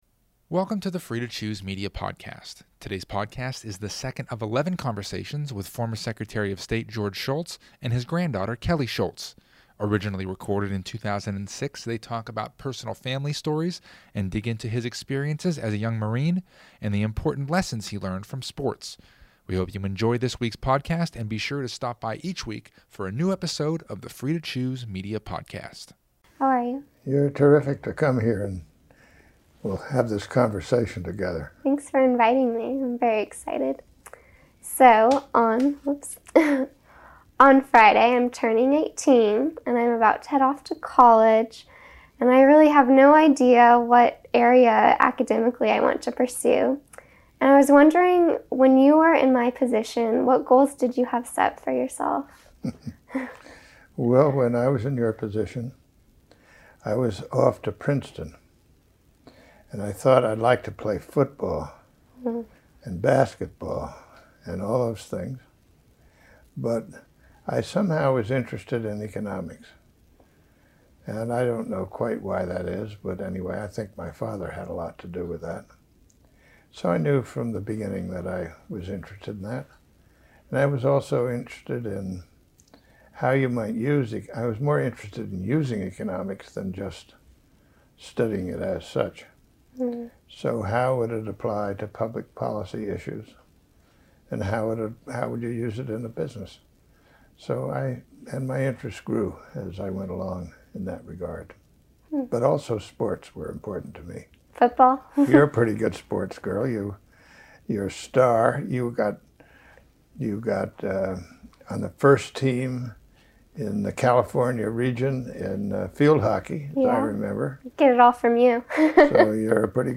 Listen in as they discuss family gatherings, his experiences as a young Marine, and the important lessons learned in sports. Originally Recorded: 2006